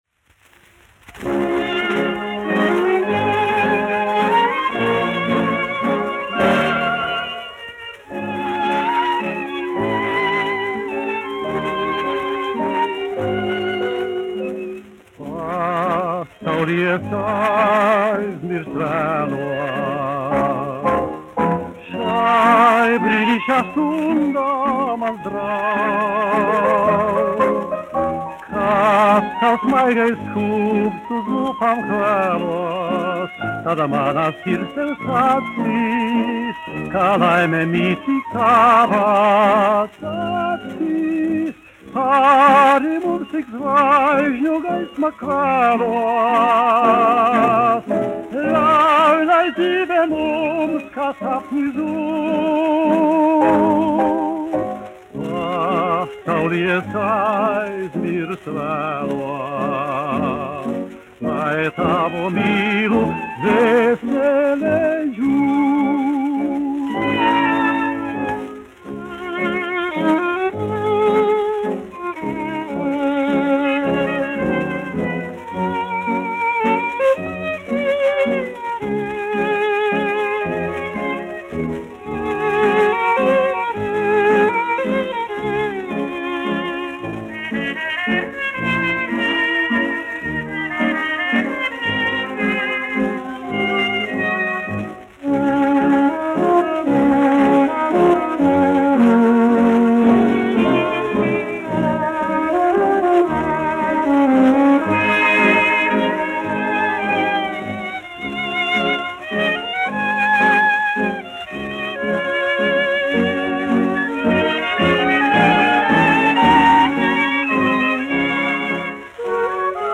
1 skpl. : analogs, 78 apgr/min, mono ; 25 cm
Valši
Populārā mūzika
Latvijas vēsturiskie šellaka skaņuplašu ieraksti (Kolekcija)